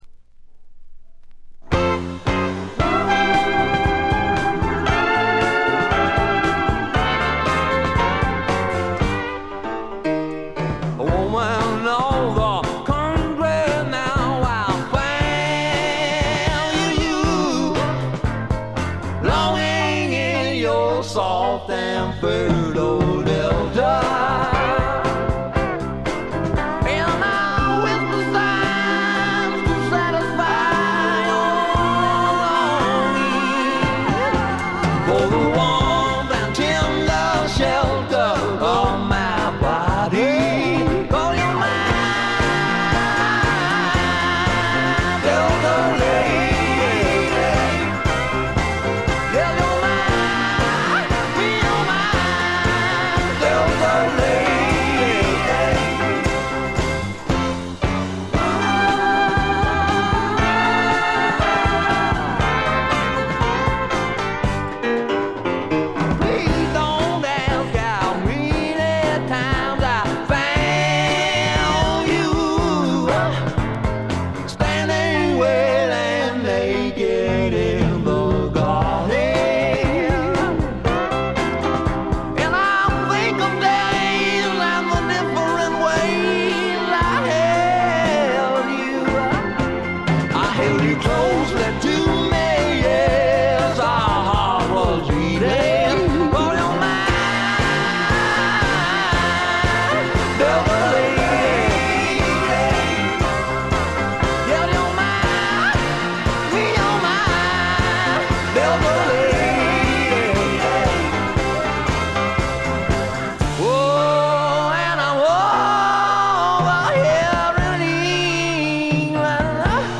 微細なチリプチ程度。
内容はいうまでもなくスワンプ風味の効いた素晴らしいシンガー・ソングライター・アルバム。
試聴曲は現品からの取り込み音源です。